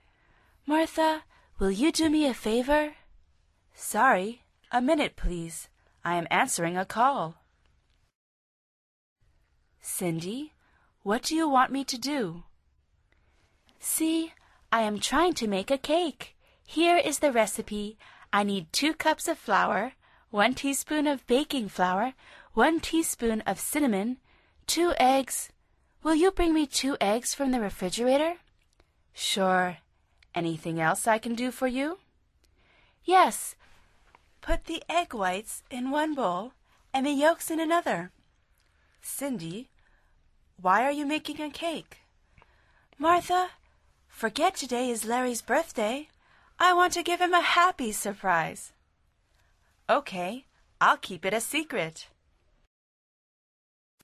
Pulsa las flechas de reproducción para escuchar el primer diálogo de esta lección. Al final repite el diálogo en voz alta tratando de imitar la entonación de los locutores.